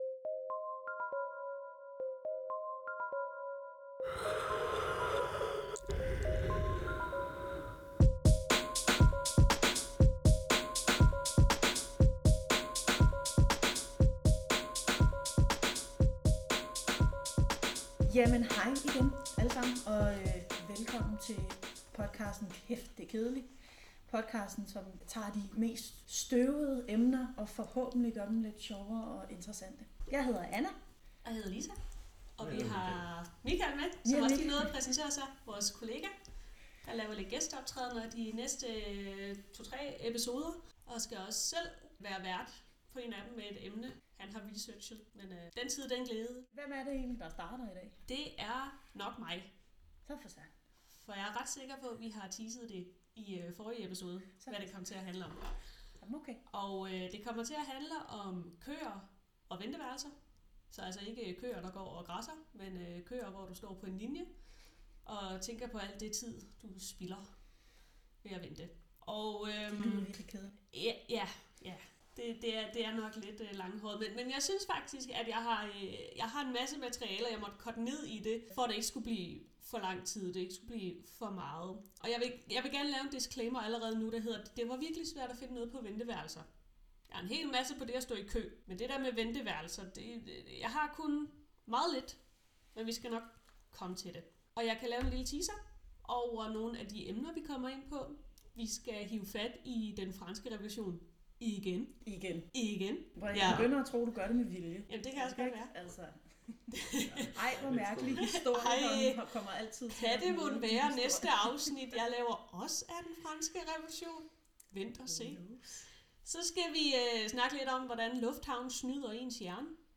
Med en blanding af saglighed, skæve vinkler og humor gør vi det tørre levende – og viser, at det kedelige faktisk kan være utroligt spændende.